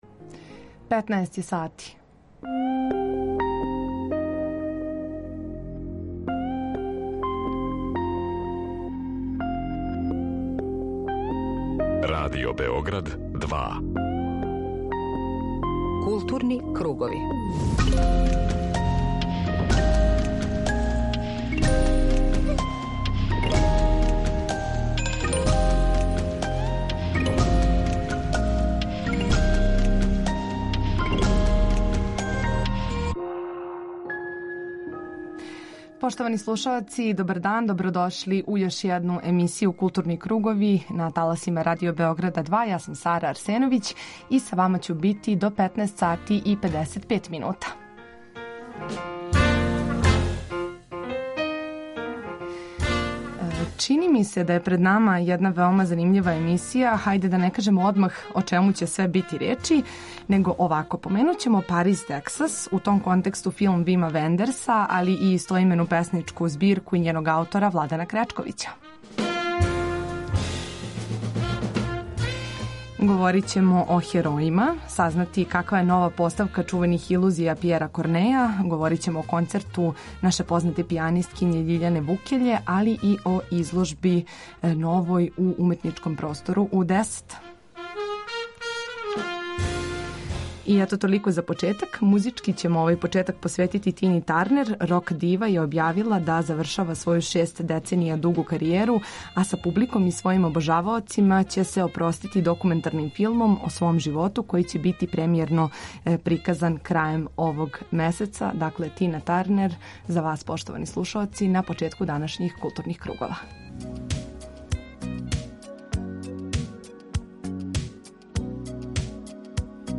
Група аутора Централна културно-уметничка емисија Радио Београда 2.
Очекује нас и укључење из београдске Галерије У10 где од данас можете видети групну изложбу „Masterpieces III", на којој ће се публици представити мастер студенти Факултета ликовних уметности.